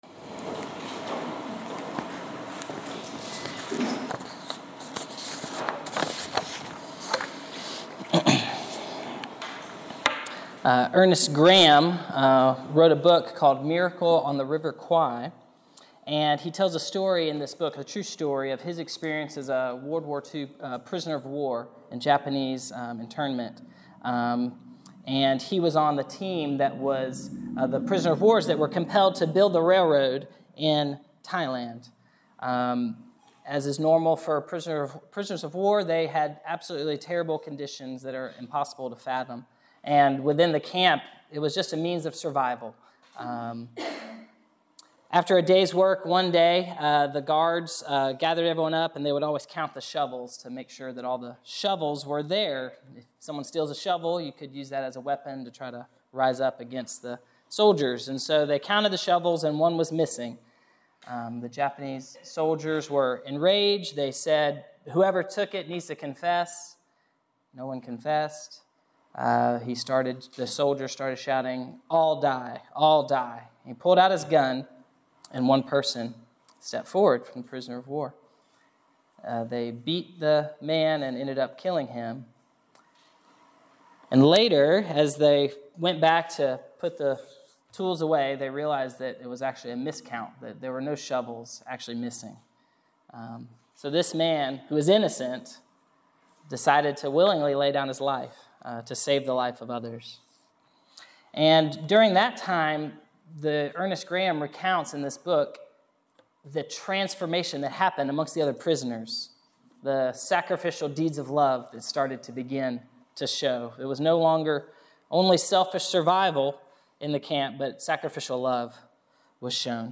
Passage: John 15:12-17 Preacher